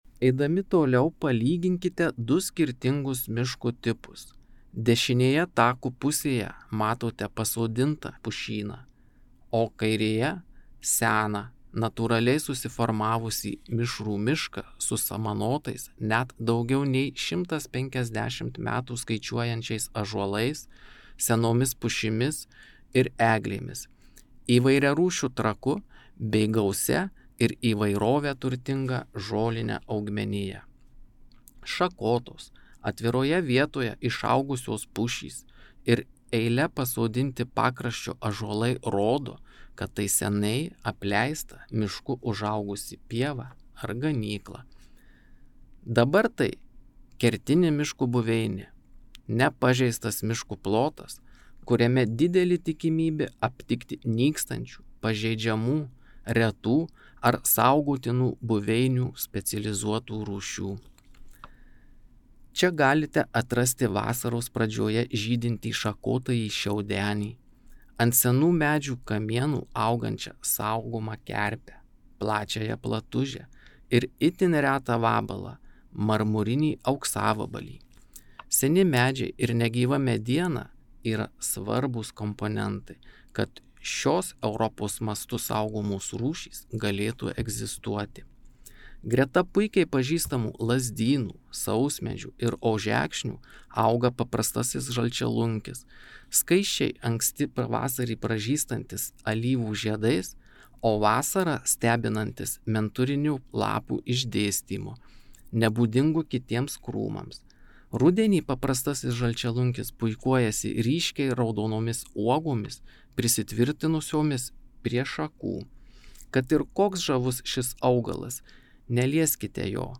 Jūs klausote miškininko pasakojimo